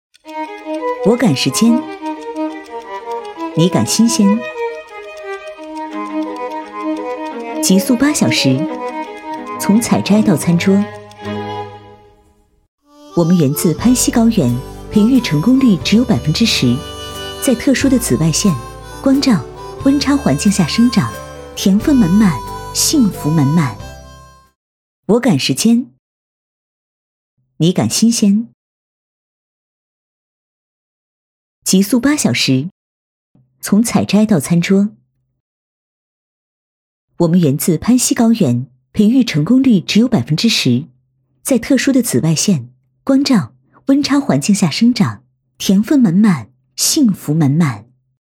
v385-【宣传片】白草莓短视频 干练
女385温柔知性配音 v385
v385--宣传片-白草莓短视频-干练.mp3